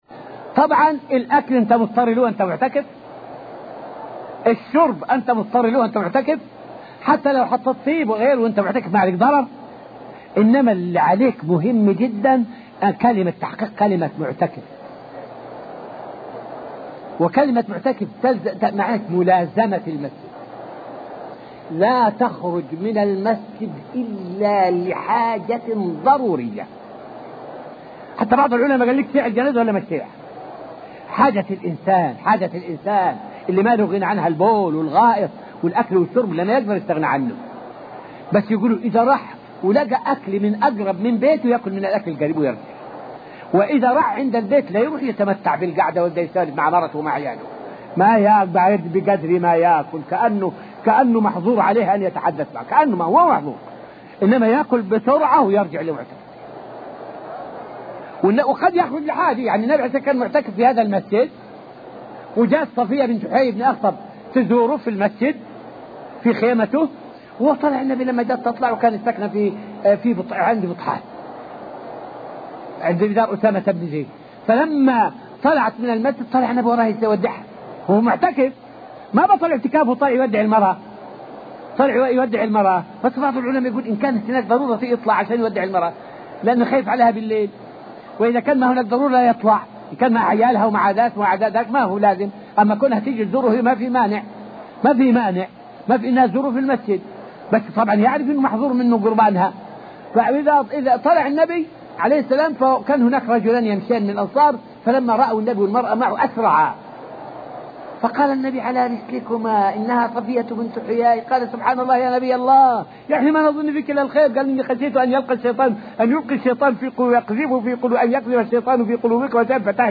فائدة من الدرس الثامن والعشرون من دروس تفسير سورة البقرة والتي ألقيت في المسجد النبوي الشريف حول المباحات للمعتكف.